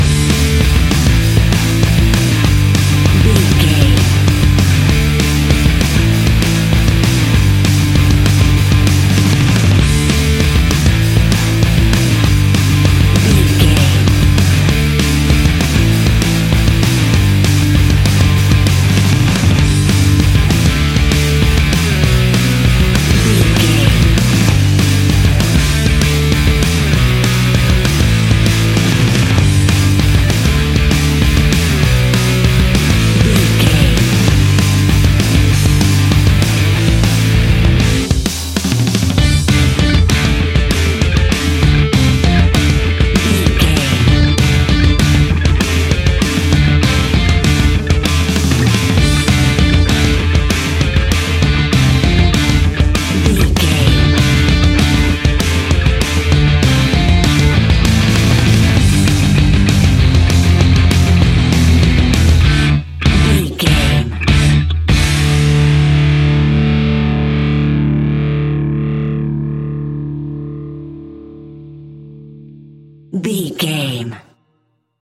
Uplifting
Fast paced
Ionian/Major
D
Fast
guitars
hard rock
distortion
punk metal
instrumentals
Rock Bass
Rock Drums
distorted guitars